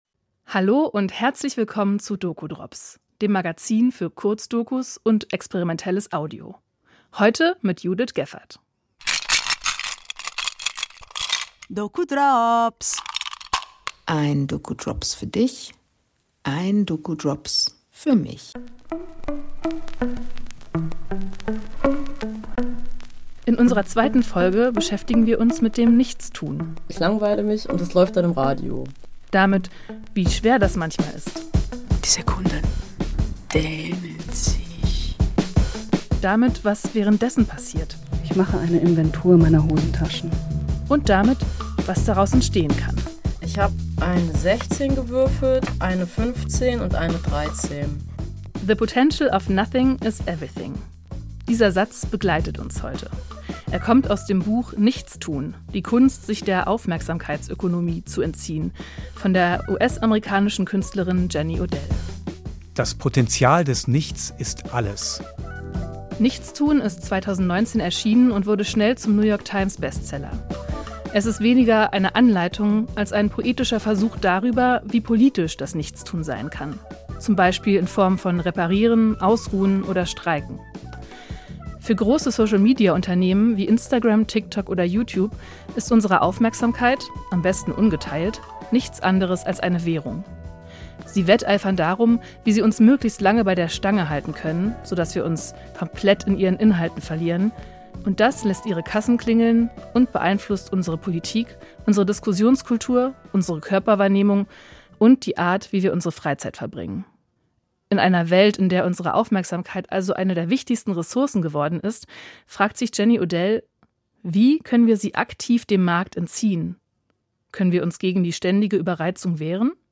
Folge 2: Kurzdokus über das Nichtstun, inspiriert von Jenny Odells Buch „How to do Nothing“